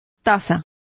Complete with pronunciation of the translation of valuation.